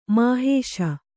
♪ māhēśa